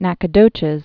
(năkə-dōchĭz)